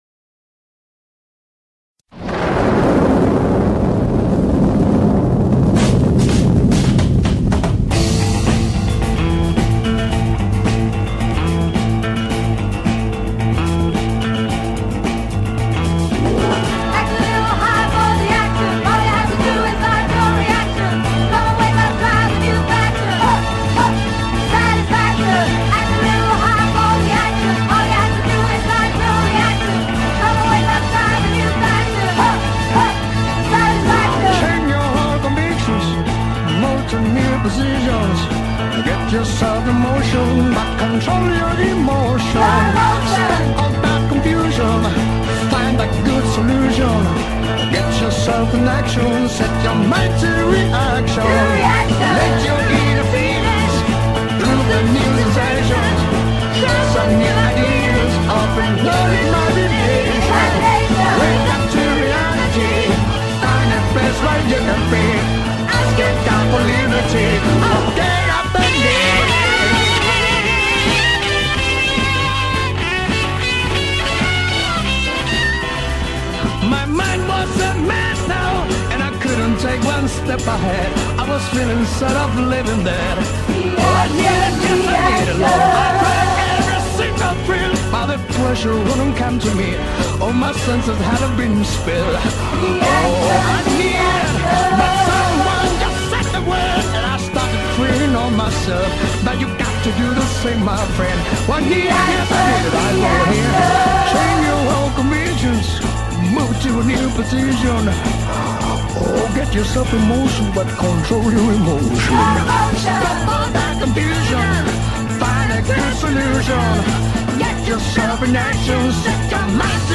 guitarra solista
órgano